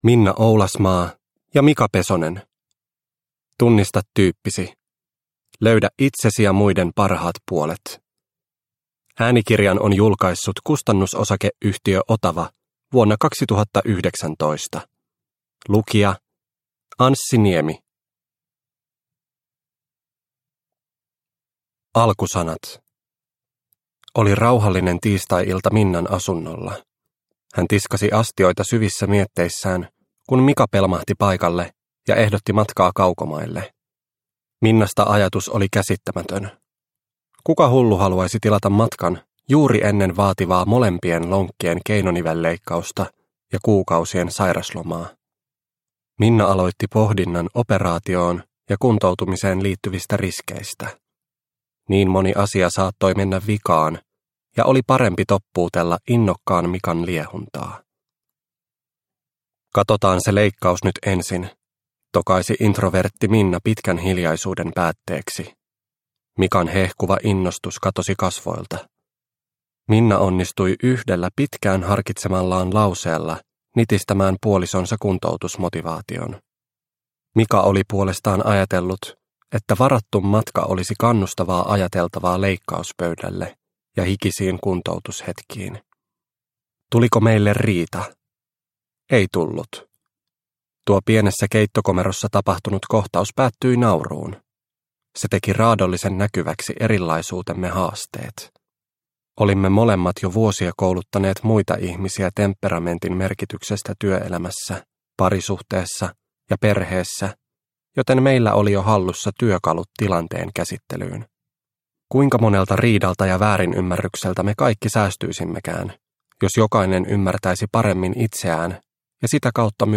Tunnista tyyppisi – Ljudbok – Laddas ner